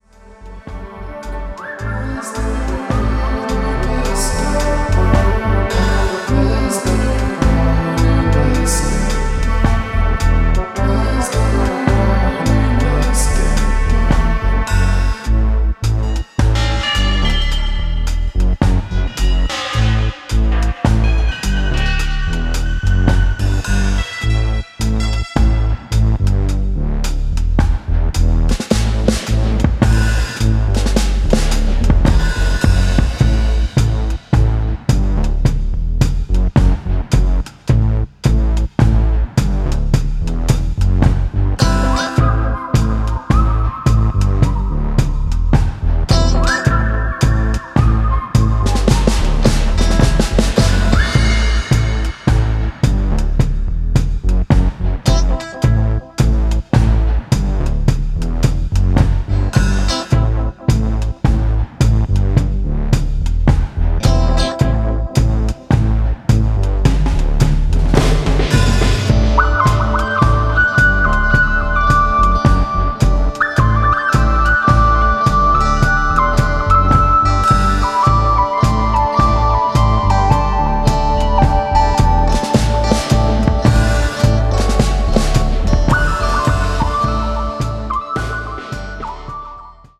Experimental